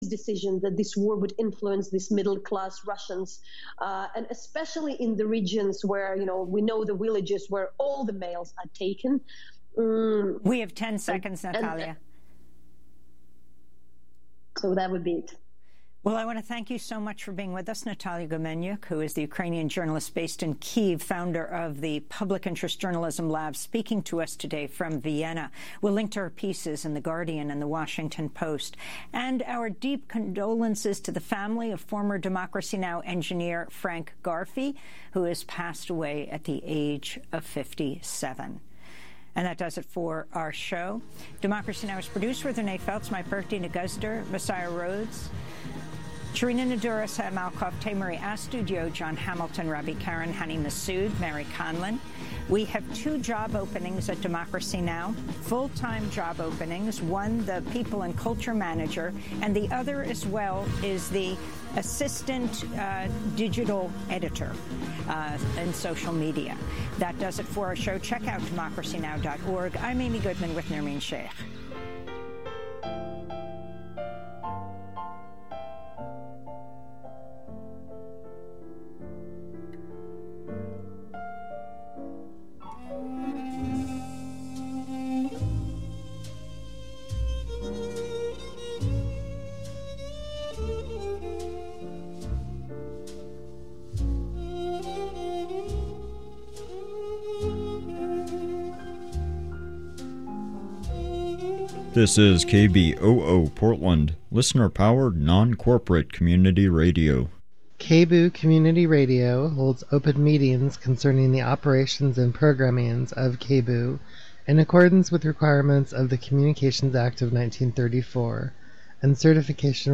At the start of our show we bring you the headlines of the day.